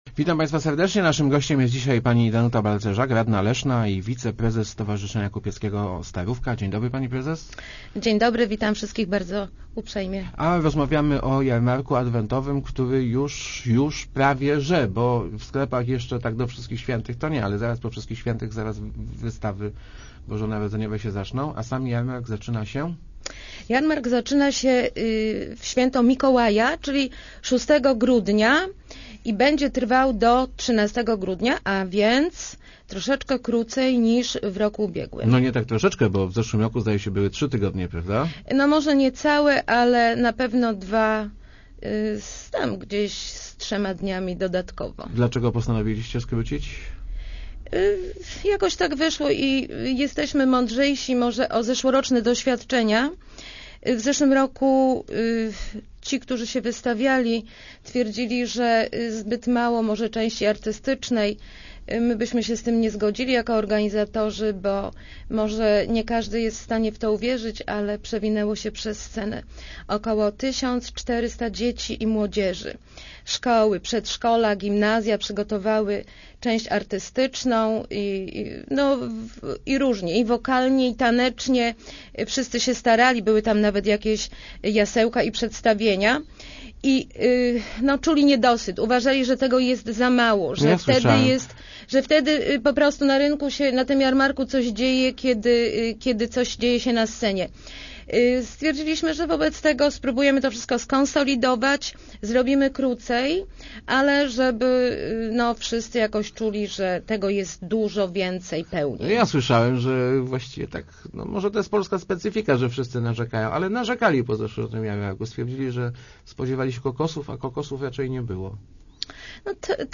balcerzak80.jpgTegoroczny Jarmark Adwentowy będzie trwał tydzień – mówiła w Rozmowach Elki Danuta Balcerzak, radna Leszna i wiceprezes Stowarzyszenia Starówka. To efekt doświadczeń ubiegłorocznych – wtedy kupcy skarżyli się, że przez ich kramy przewinęło się zbyt mało klientów.